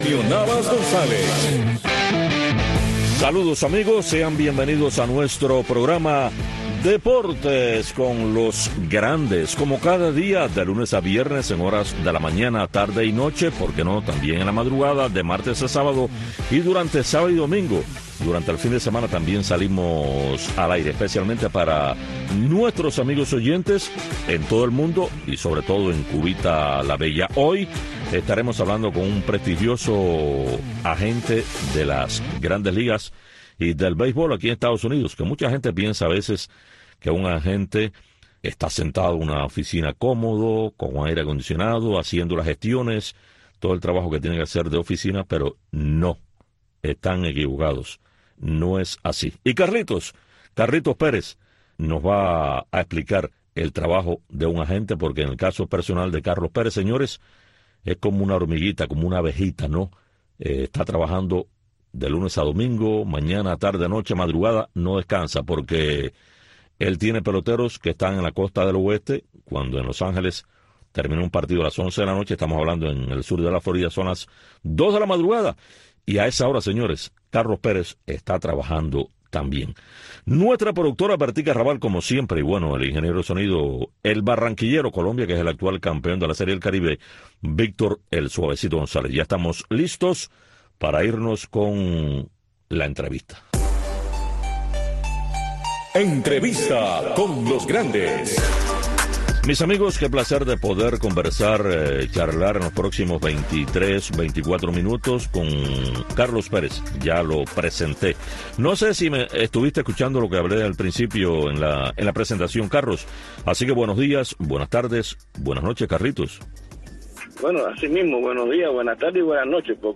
Entrevistas, comentarios y análisis de los grandes acontecimientos del deporte.